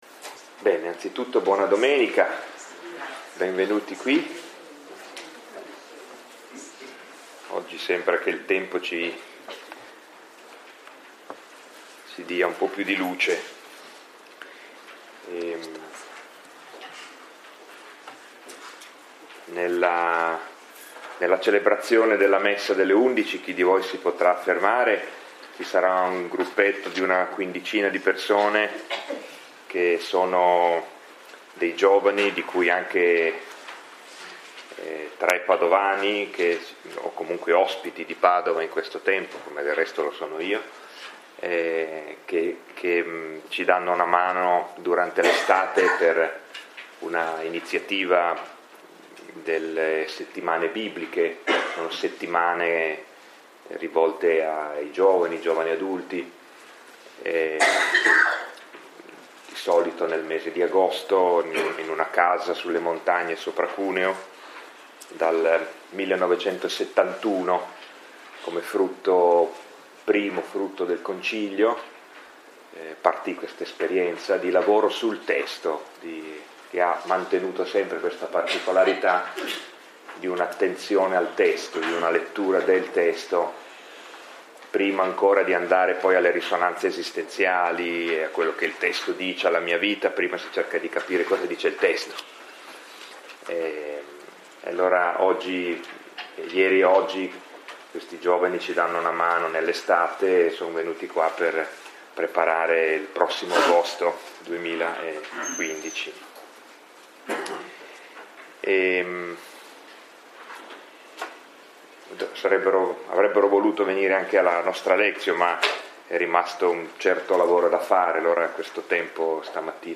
Lectio 5 – 15 febbraio 2015 – Antonianum – Padova